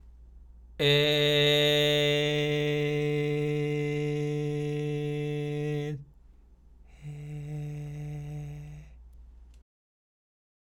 ステップ1：仮声帯がきちんと機能したジリジリ音が入った声（グーの声）を出し、そこから一度仮声帯を引っ込めた状態（ニヤけた状態）を作りチョキにする。
そのニヤけた状態を保ったまま声帯だけを緩め、息漏れのあるパーの声で「え（へ）」と出す。